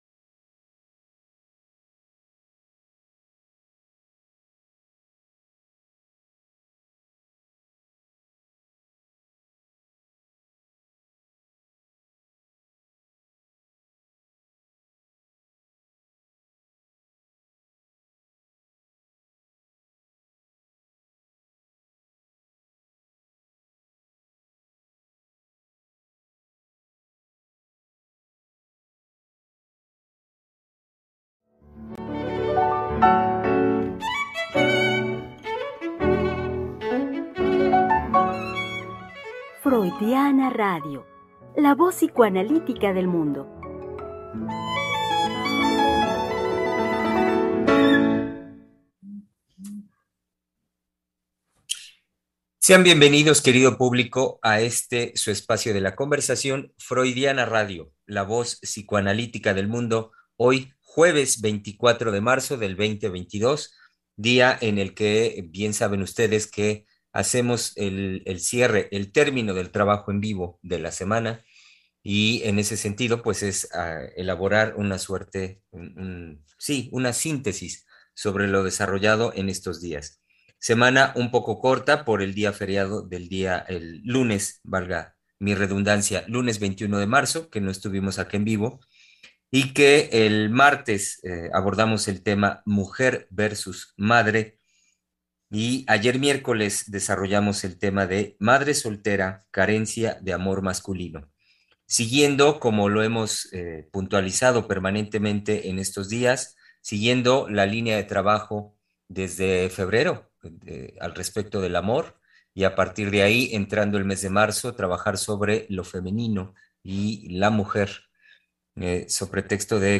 Acompáñanos a Tres Mujeres Psicoanalistas Hablando de la Vida Cotidiana conversaremos con